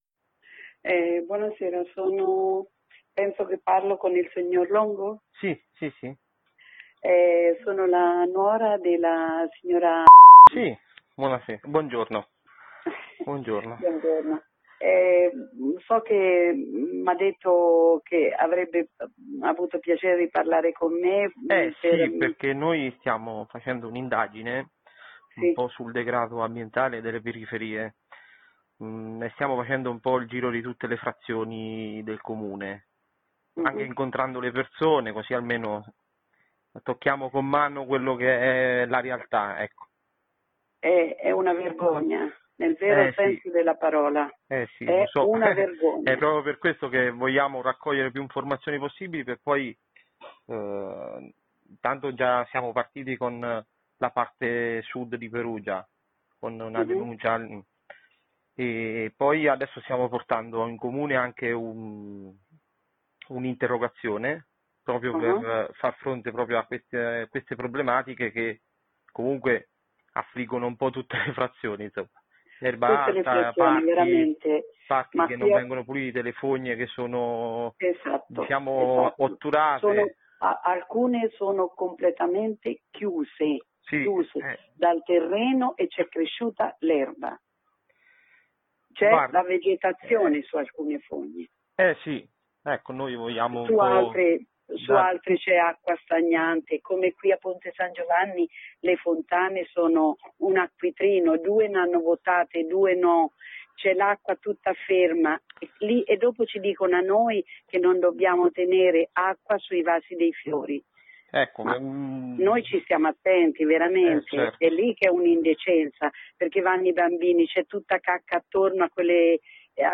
audio-signora-punta-dalla-zecca.mp3